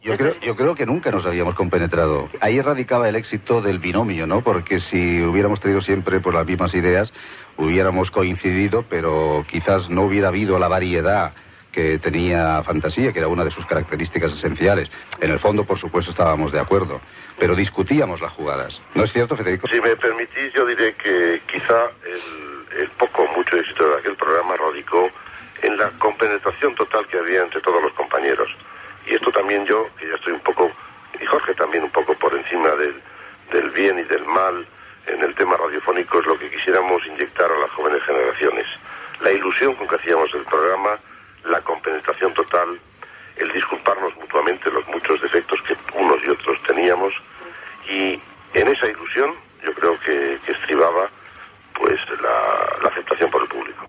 Programa presentat per Joan Manuel Serrat